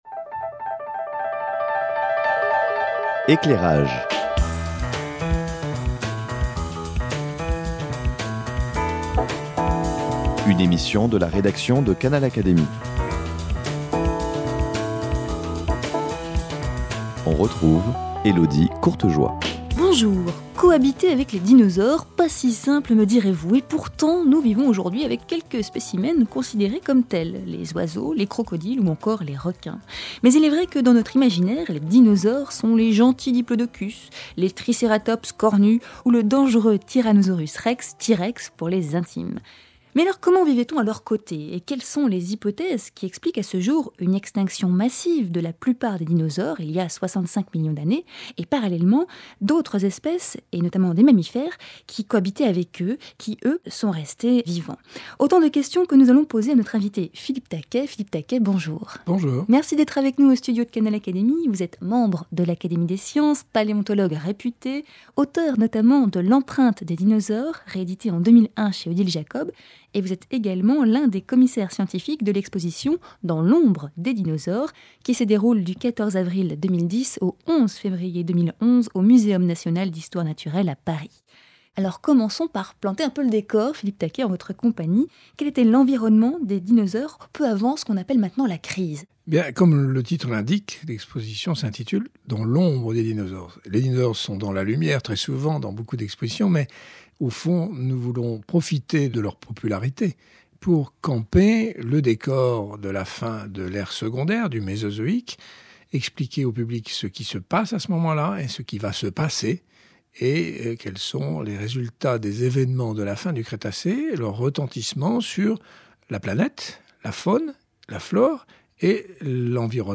Les dinosaures Bambiraptor et SuperCroc racontés par Philippe Taquet, membre de l’Académie des sciences !
Philippe Taquet nous raconte dans cette émission l'histoire de plusieurs dinosaures visibles dans l'exposition.